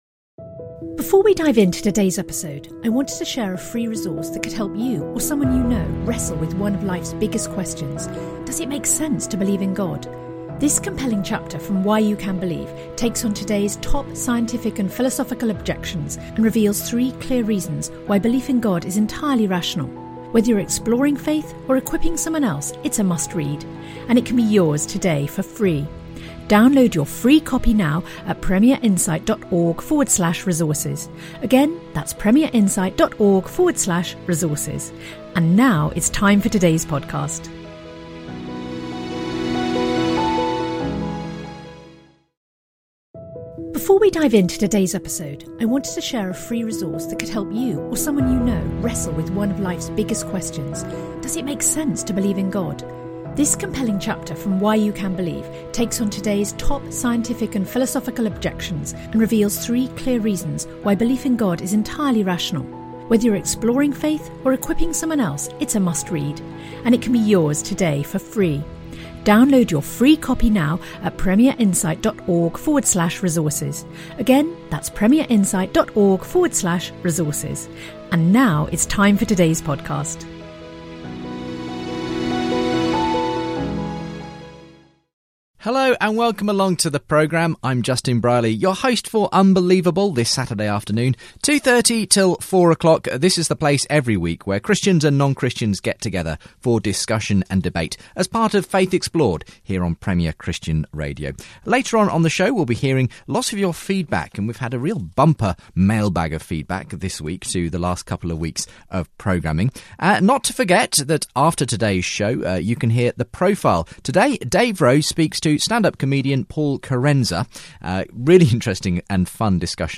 In this edition of the show we swap the pub for the studio and address the question of whether it’s possible to build a flourishing society in the absence of a Christian or theistic framework.